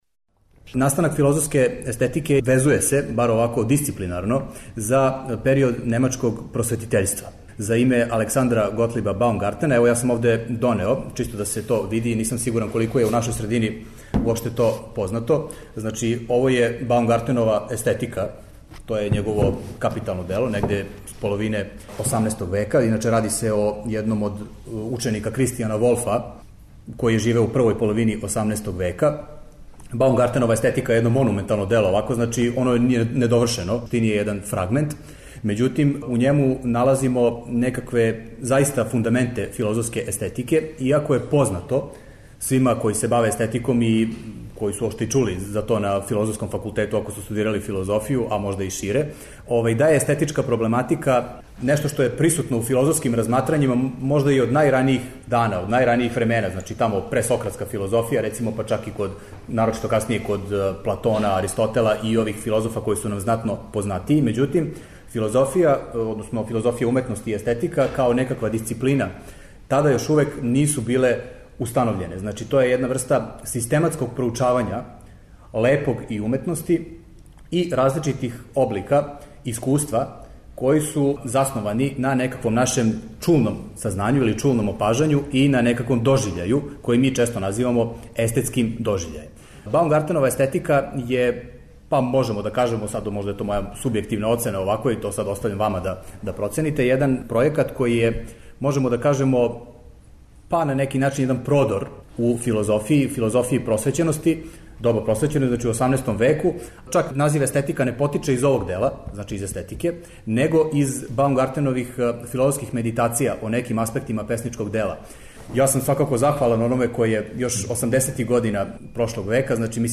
Предавањa